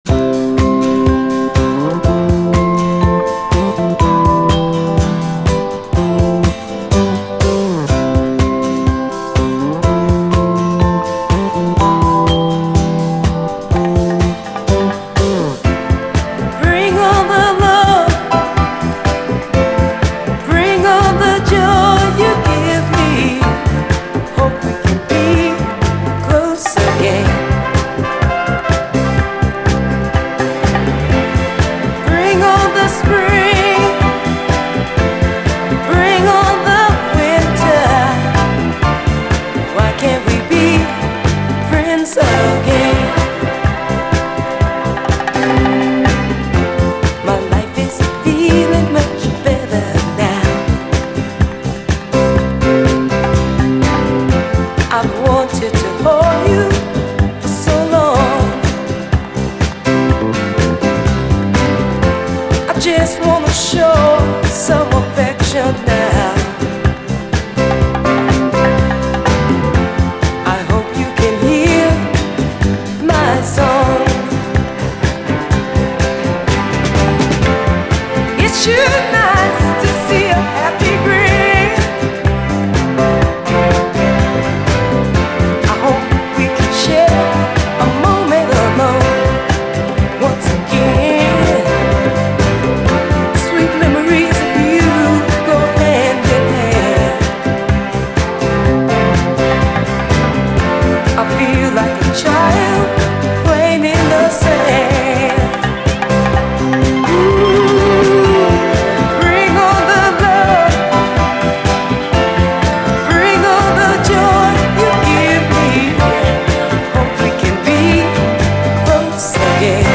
SOUL, 70's～ SOUL
ノーザン・ソウルらしさも香る70’Sエレガント・ソウル！